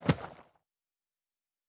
Fantasy Interface Sounds